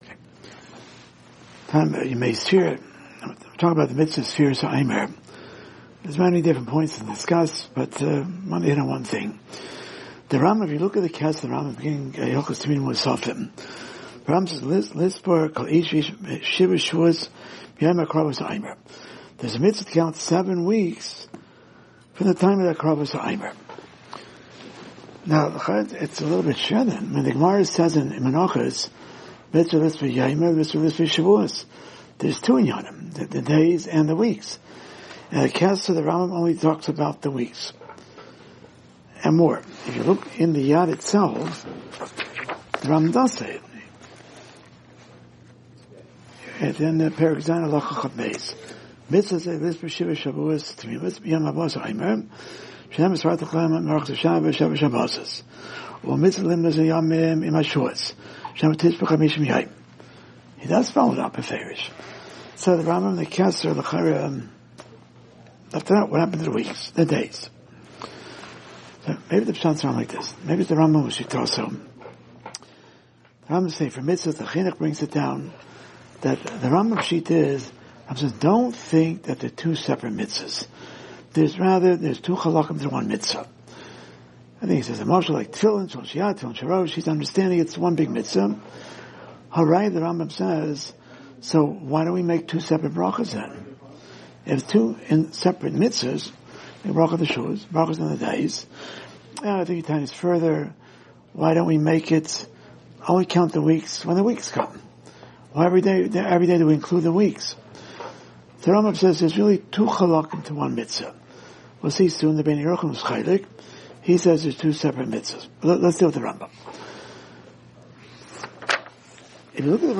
Weekly Alumni Shiur Sefiras Haomer 5785